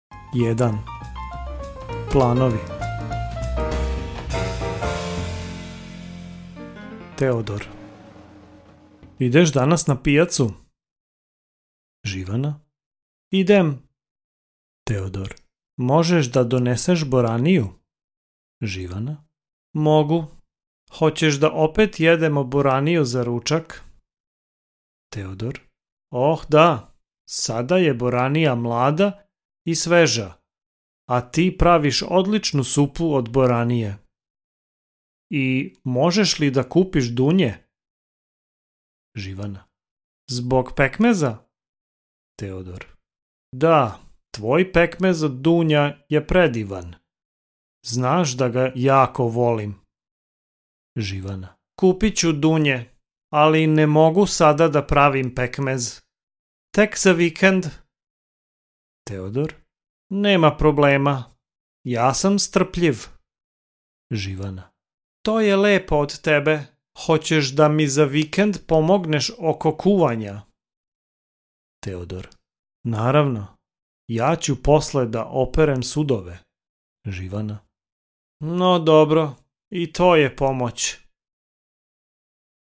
Audiobook in Serbian